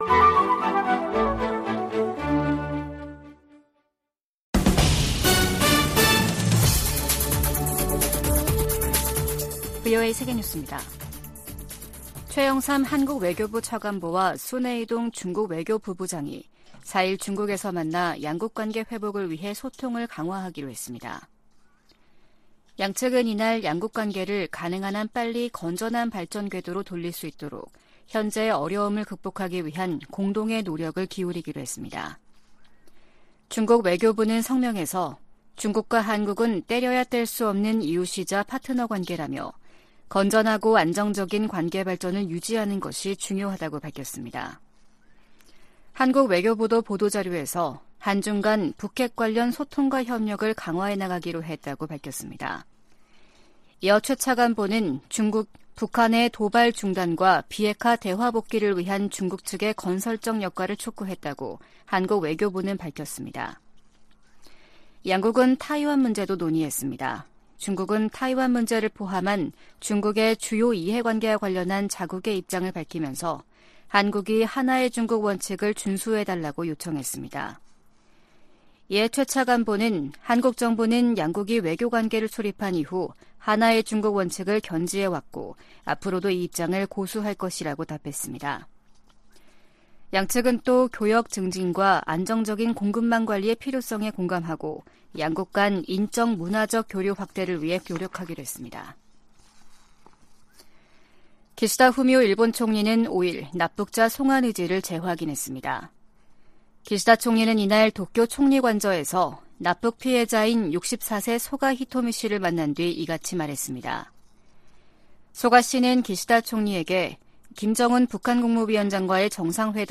VOA 한국어 아침 뉴스 프로그램 '워싱턴 뉴스 광장' 2023년 7월 6일 방송입니다. 한국과 중국이 시진핑 국가주석 3연임 확정 후 첫 고위급 공식 회담을 가졌습니다. 북한과 일본이 정상회담을 염두에 두고 고위급 접촉을 모색하고 있습니다. 북대서양조약기구(NATO·나토) 정상회의를 앞두고 미국과 한국의 나토 대사가 만나 협력 방안을 논의했습니다.